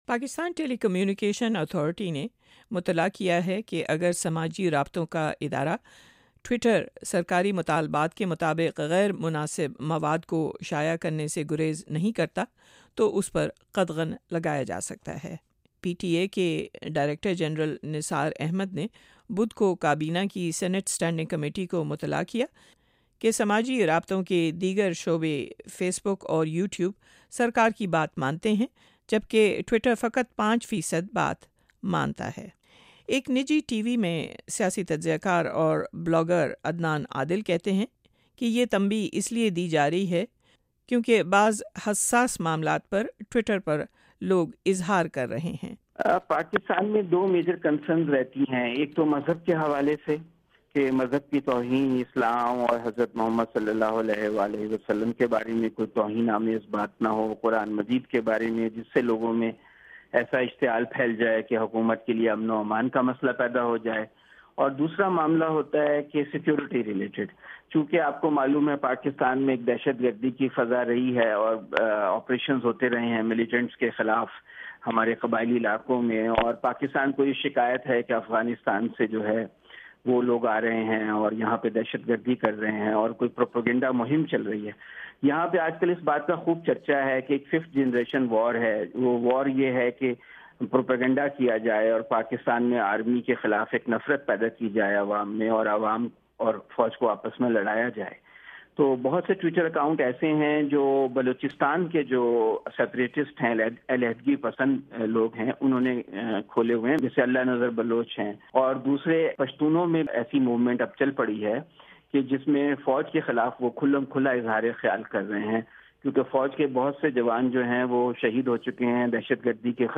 پاکستان میں صورت حال پر نظر رکھنے والے تجزیہ کاروں سے گفتگو کی۔